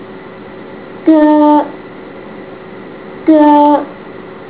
下列介绍太魯閣族基本語言的發音，並列出太魯閣語字母表及發音表。